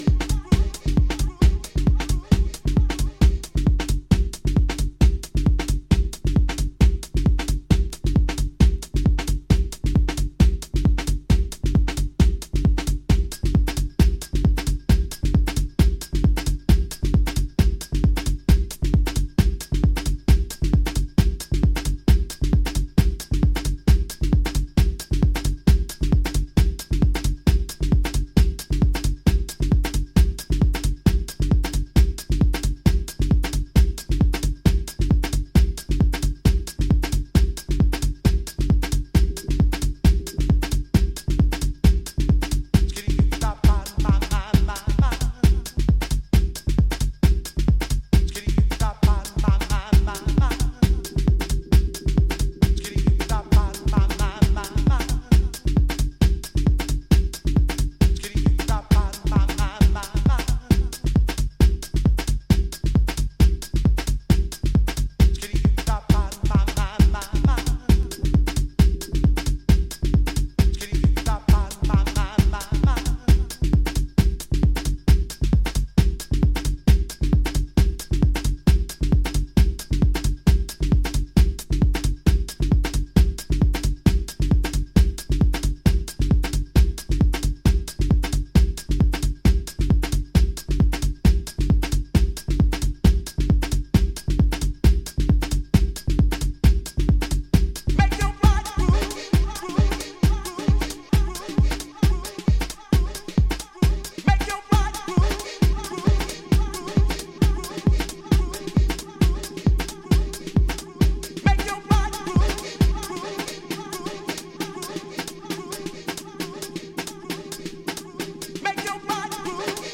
another seriously big minimal mover
Electro
Techno